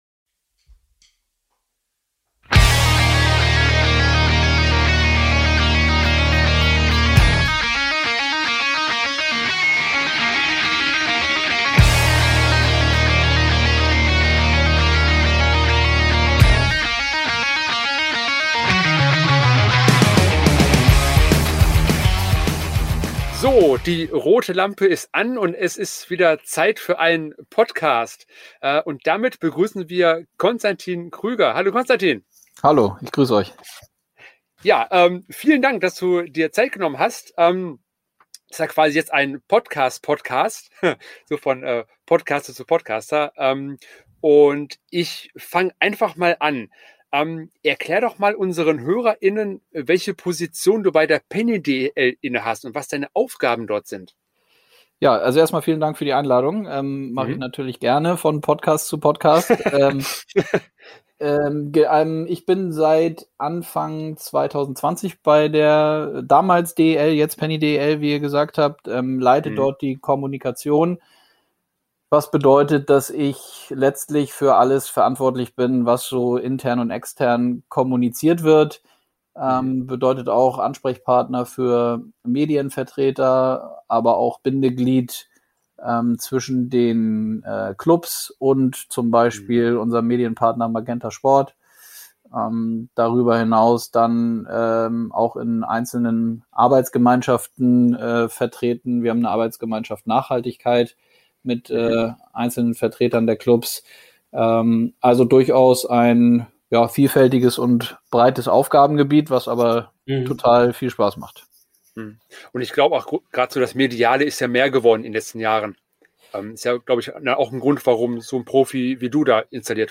Lake Cisco aus Bad Neuenahr machen laut eigener Aussage „Prog Indie“.